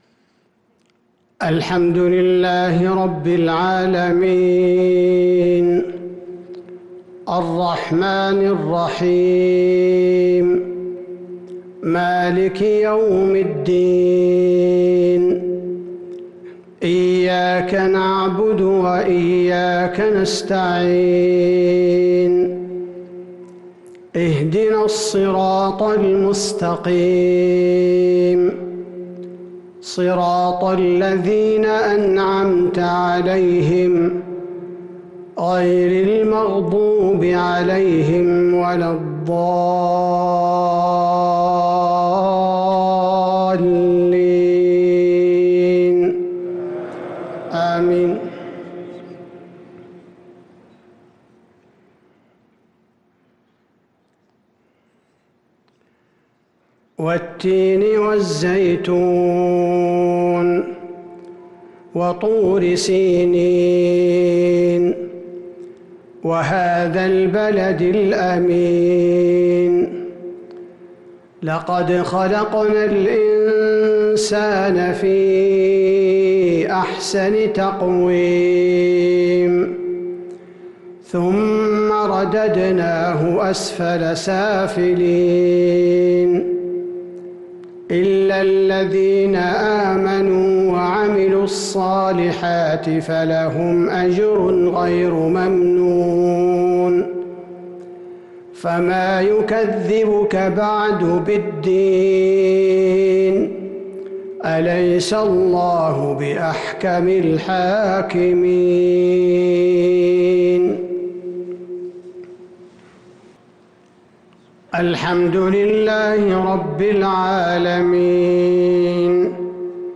صلاة المغرب ٥ ذو القعدة ١٤٤٣هـ سورتي التين والناس | Maghrib prayer from Surah at-Tin & an-Nas 4-6-2022 > 1443 🕌 > الفروض - تلاوات الحرمين